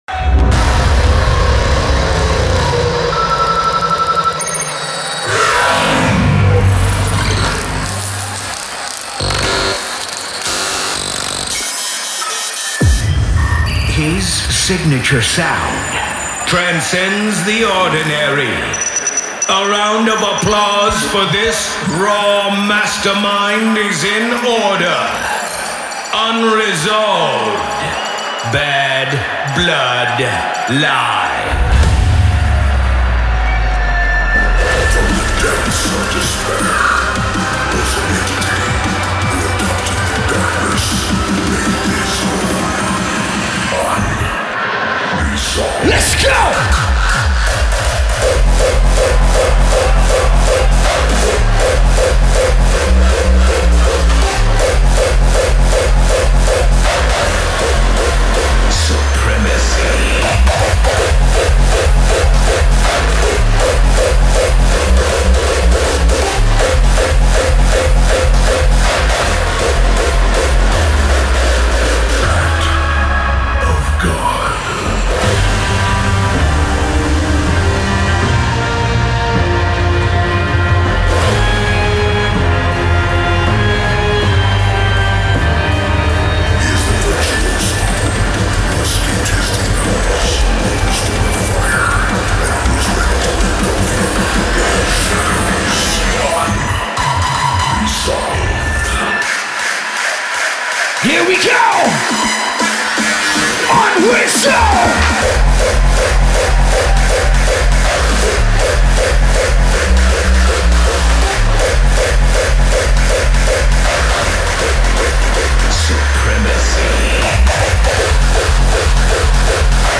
DJ mix
Live Set